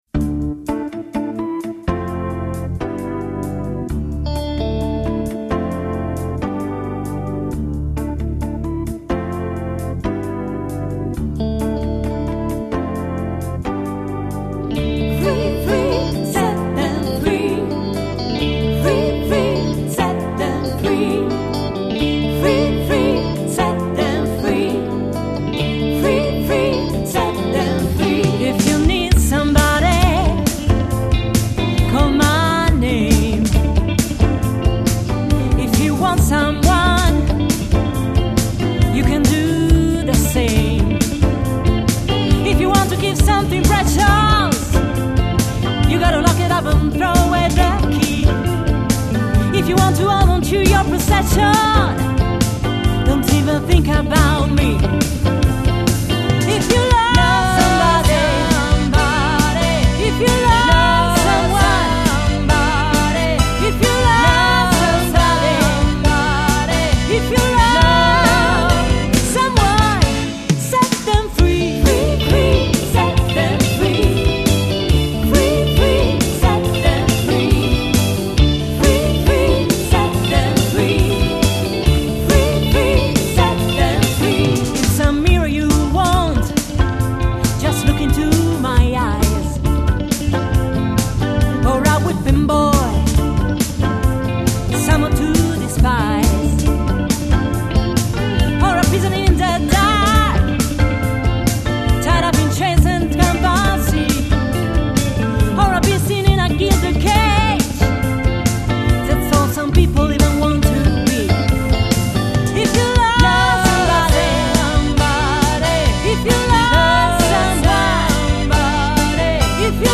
ARE YOU READY TO ROCK ?